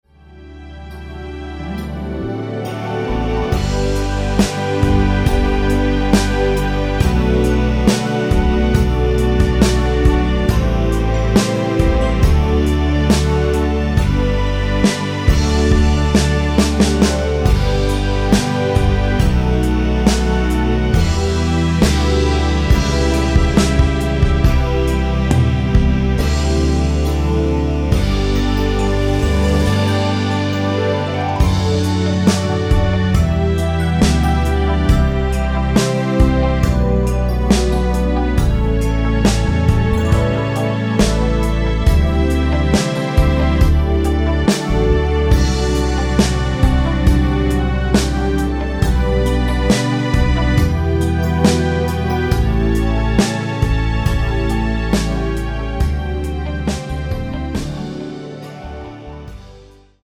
키 G